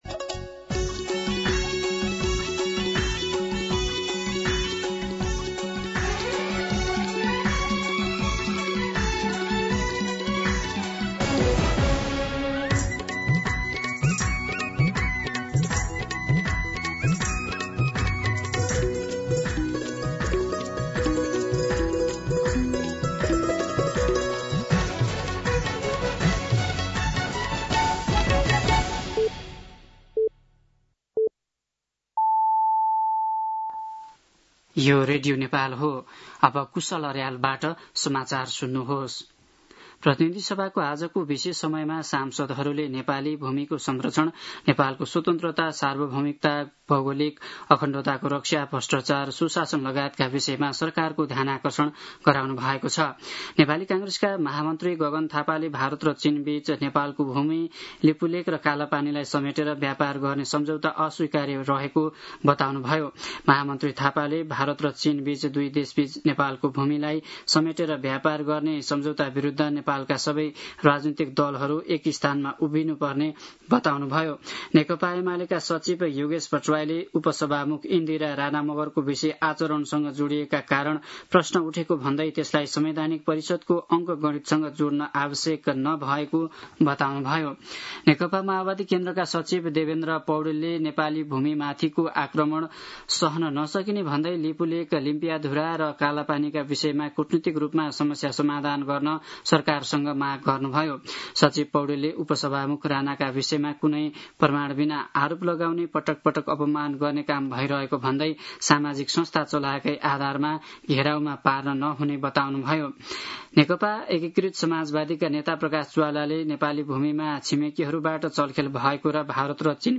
दिउँसो ४ बजेको नेपाली समाचार : ५ भदौ , २०८२
4pm-News-05-5.mp3